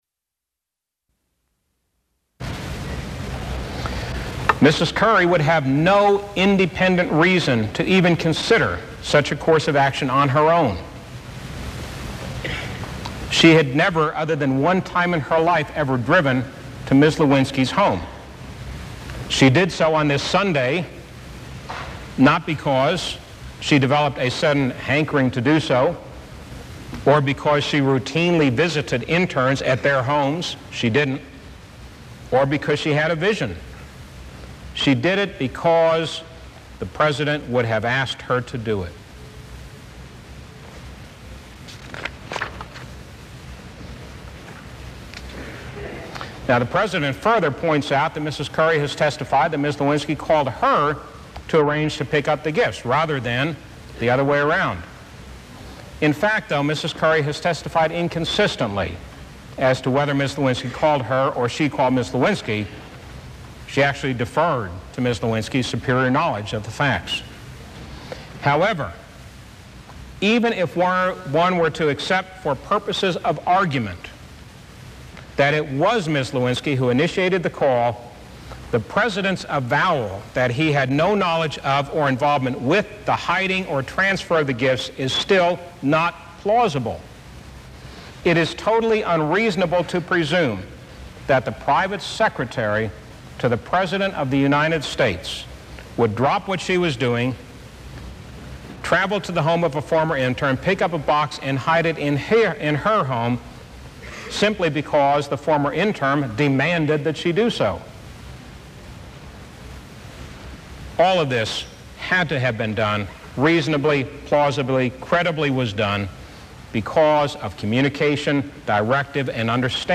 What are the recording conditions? Broadcast on NPR, January 15, 1999.